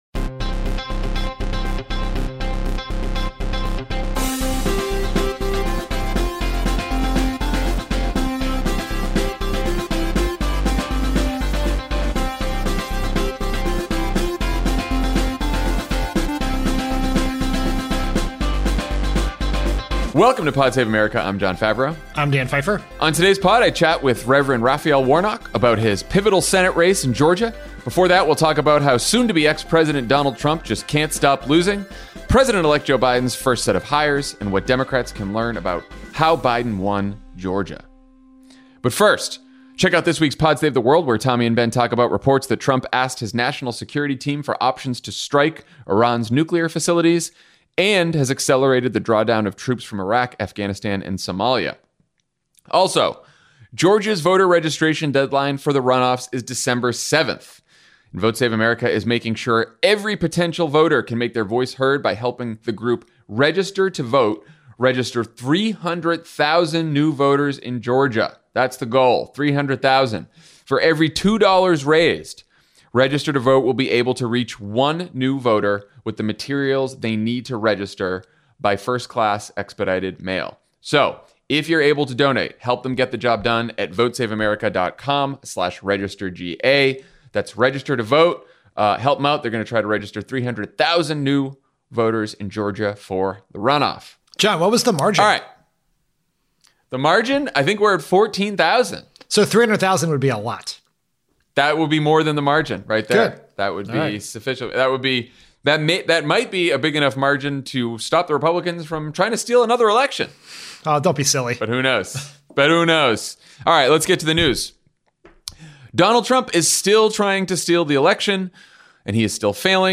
Trump and Republicans continue their failing scheme to steal the election, Joe Biden makes his first round of White House hires, and Democrats figure out how to keep Georgia blue. Then Reverend Raphael Warnock talks to Jon about how he plans to win his pivotal Senate race in Georgia.